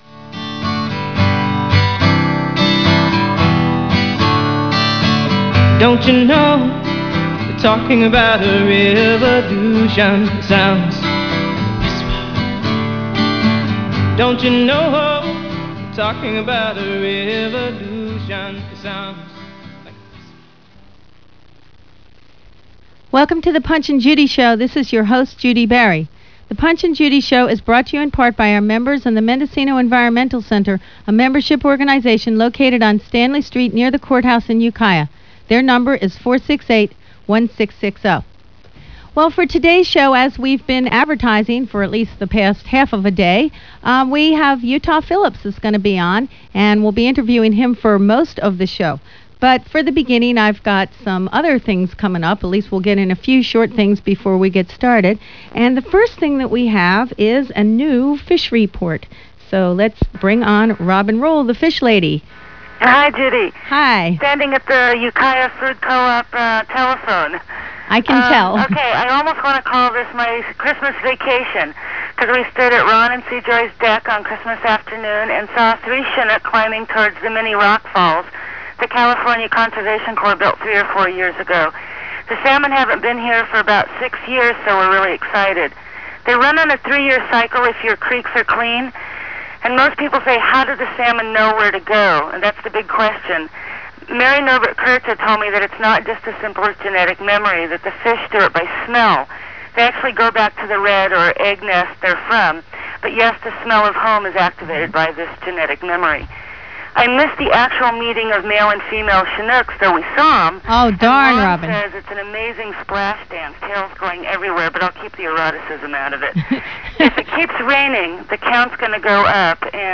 Punch & Judi Show: Judi Bari interviews Utah Phillips on KZYX FM in Boonville, California - December 27, 1996